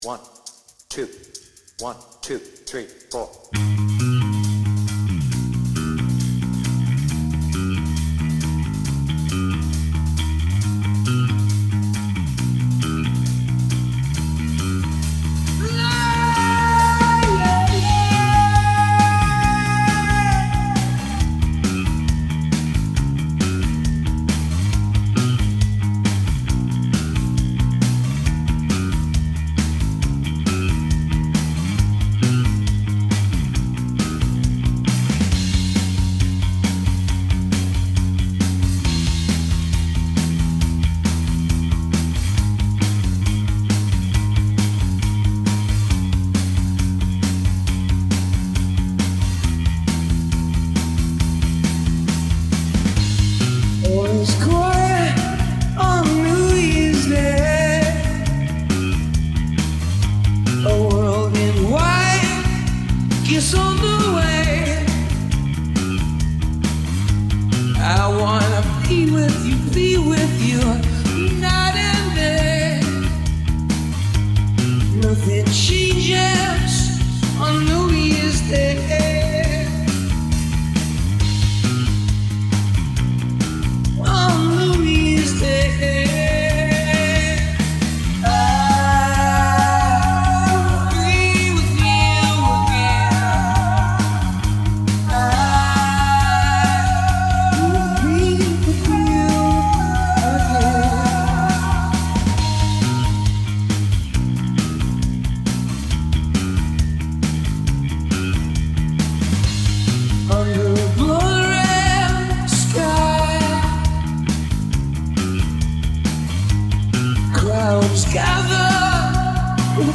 Guitar Backing Track Without Piano
BPM : 136
Tuning : Eb
With vocals
Based on the Slane Castle version